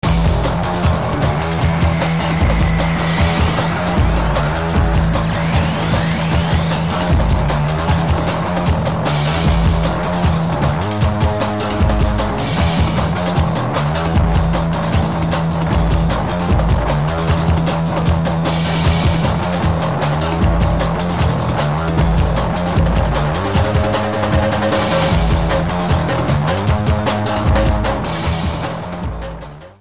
bas en gitaar
beukende dancerock